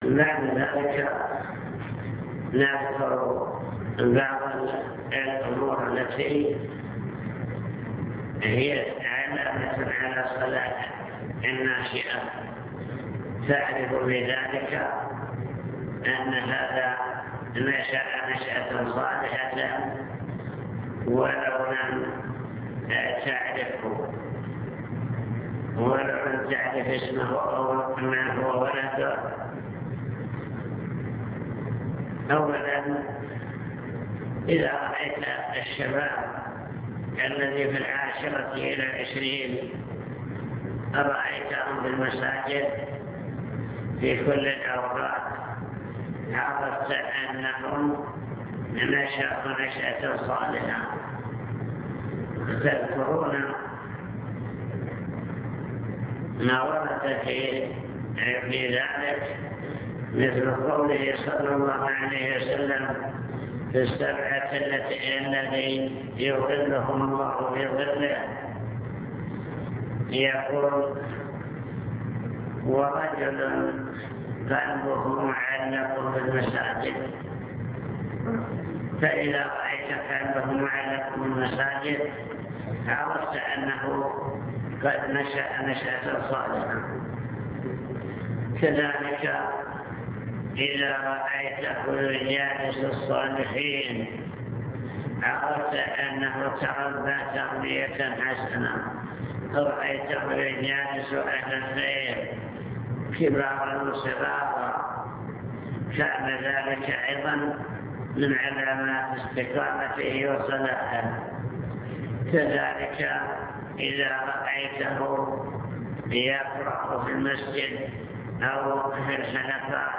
المكتبة الصوتية  تسجيلات - محاضرات ودروس  محاضرة بعنوان توجيهات للأسرة المسلمة